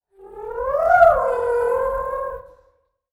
Fairy_voice_5.wav